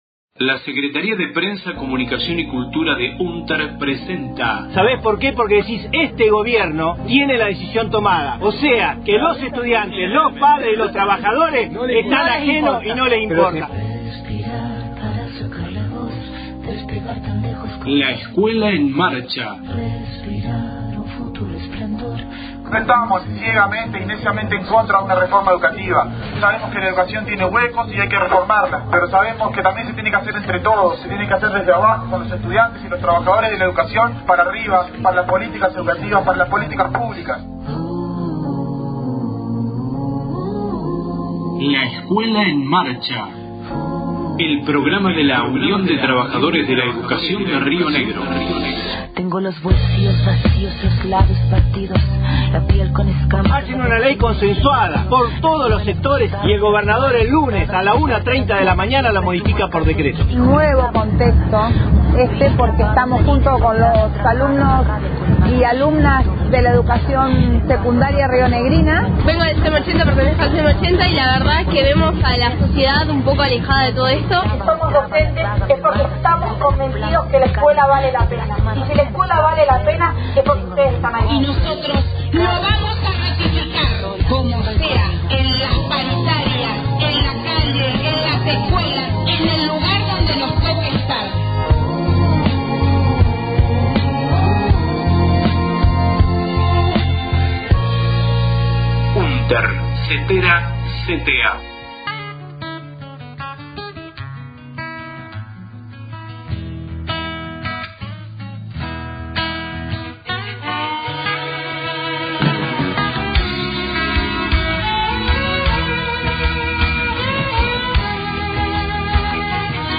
• Sin gas en la Escuela Laboral 7 y sin luz en la Especial 3 de Cinco Saltos, más de quince días sin clases, audio de la movilización que realizó la comunidad educativa el 3/10/18.
La Escuela en Marcha La Escuela en Marcha Radio Departamento de Salud en la Escuela